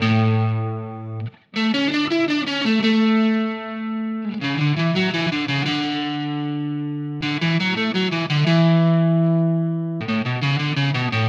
Indie Pop Guitar 05.wav